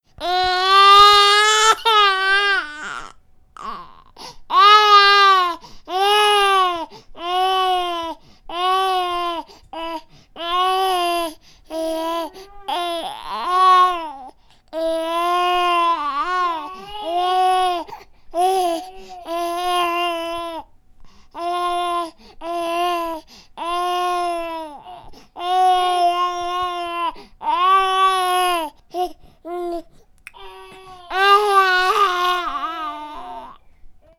افکت صوتی گریه نوزاد mp3